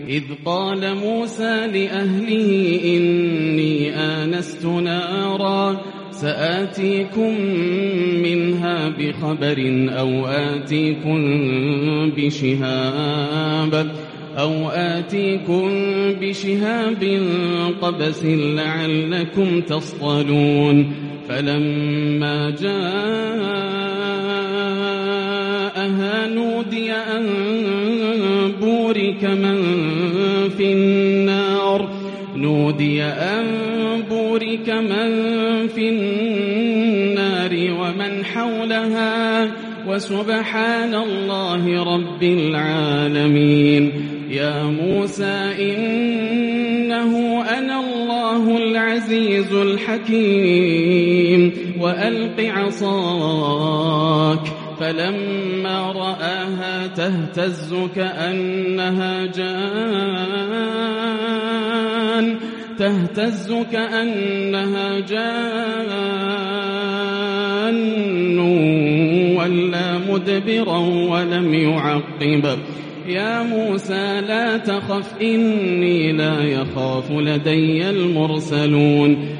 تلاة إبداعية من سورة النمل روائع ليلة 23 رمضان 1442هـ > الروائع > رمضان 1442هـ > التراويح - تلاوات ياسر الدوسري